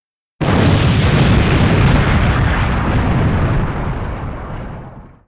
Explosion